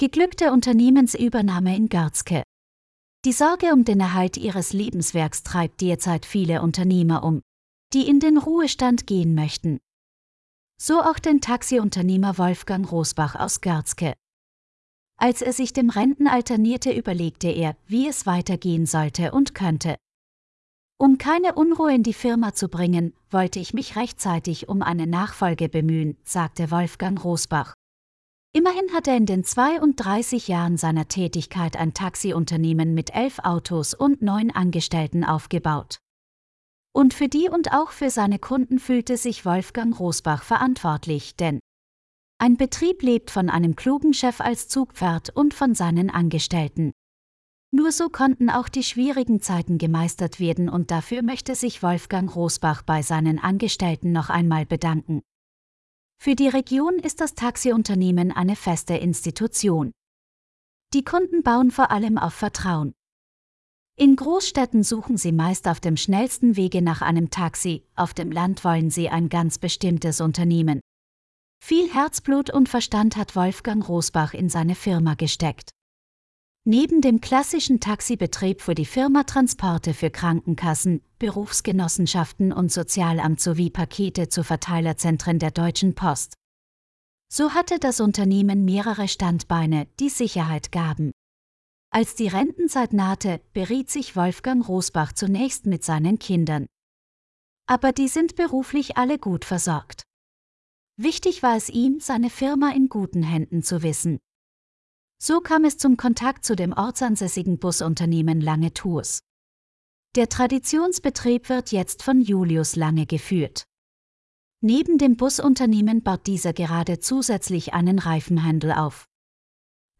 Ab sofort können Sie einige unserer Artikel auch hören. Eine KI macht es möglich.